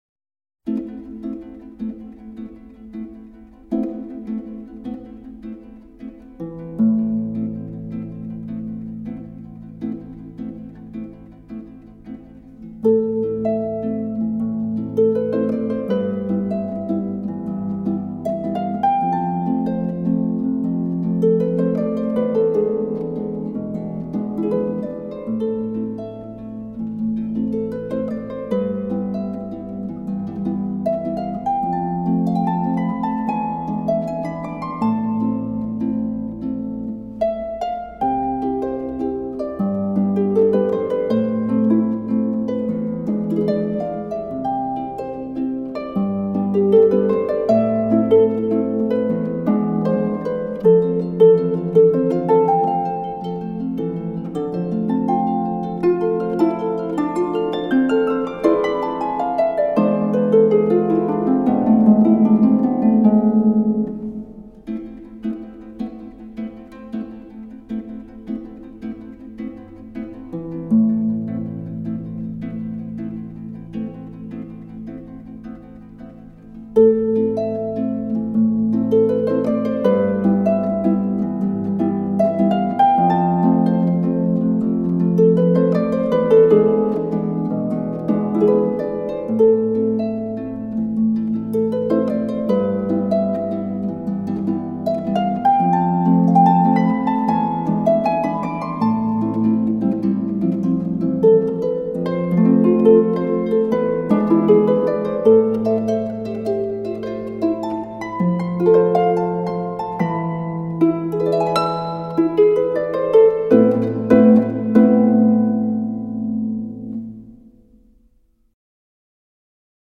實現聆賞極致的豎琴發燒碟
豎琴音樂近百年來，最徹底的超現代主義表現 豎琴
這張專輯展現出豎琴寬廣、令人興奮的面貌